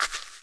hitsand1.wav